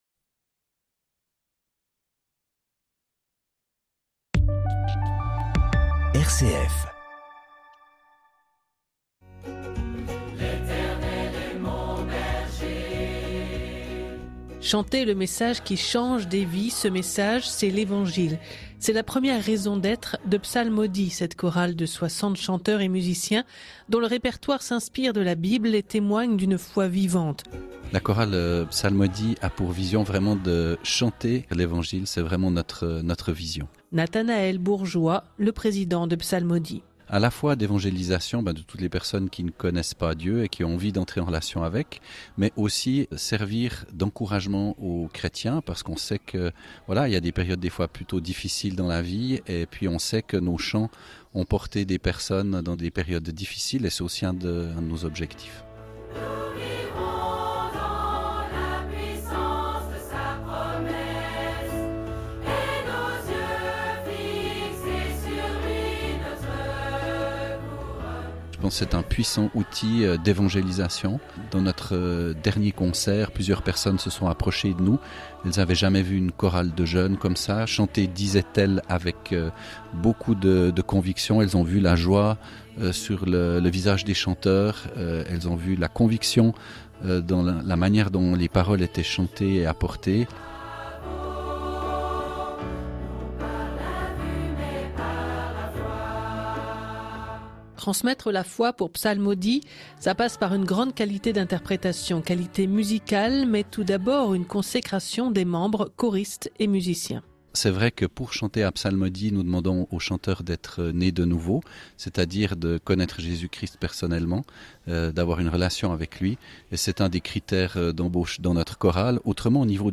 Reportage sur Psalmodie sur la radio chrétienne RCF, à l’occasion de la tournée dans le sud-ouest de la France en mai 2024.